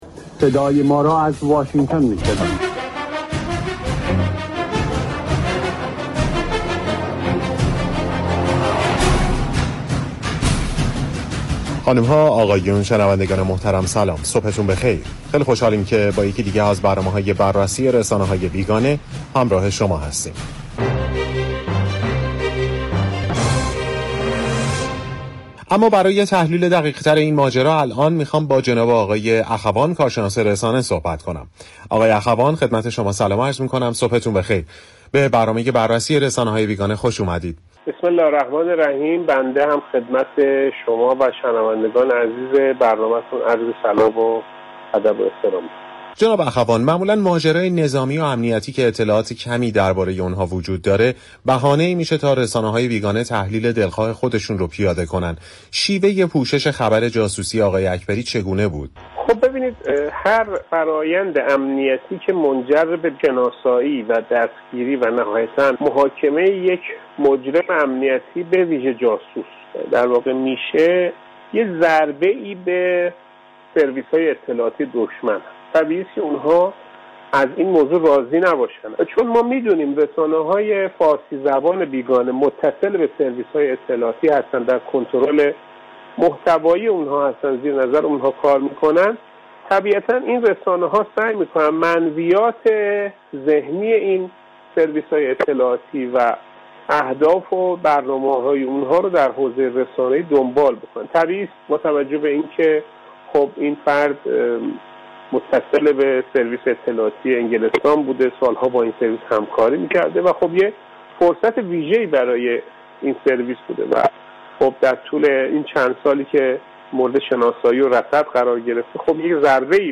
گفت‌وگوی رادیو ایران